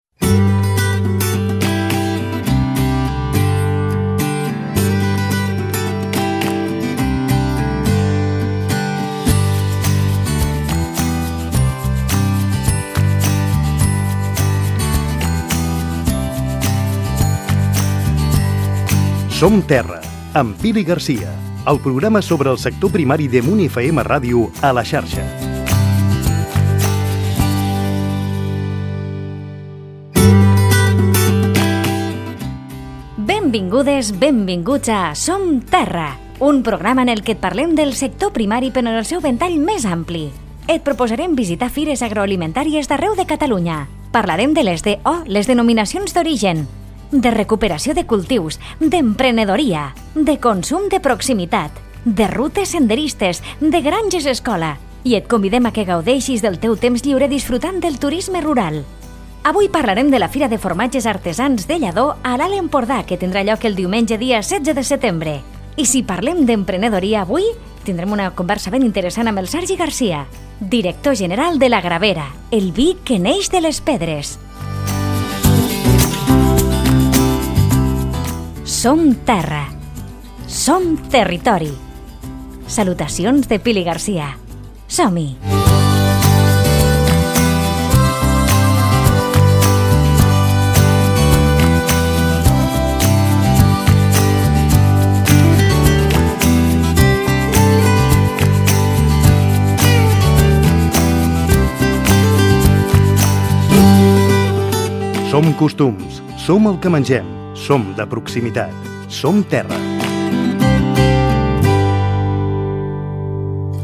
Careta del programa sobre el sector primari. Presentació, informació de la fira de formatges artesans de Lladó i el vi de la Gravera
Divulgació